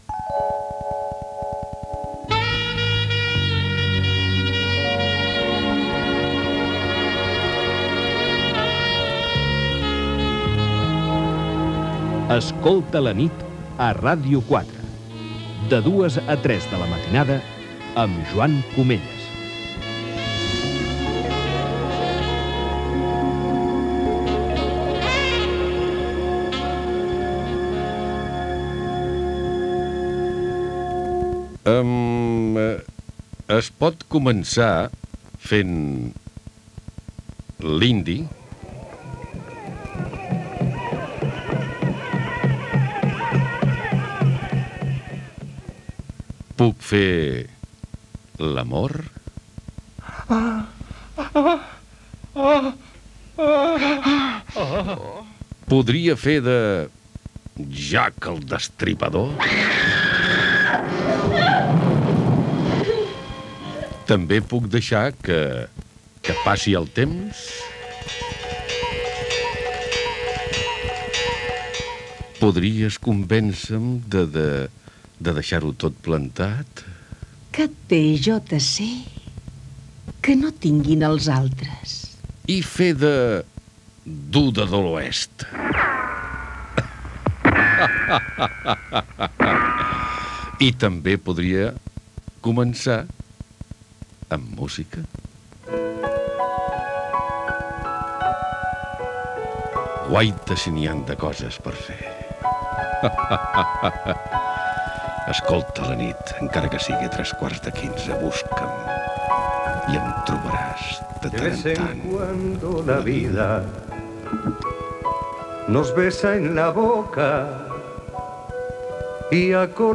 Inici del programa Gènere radiofònic Musical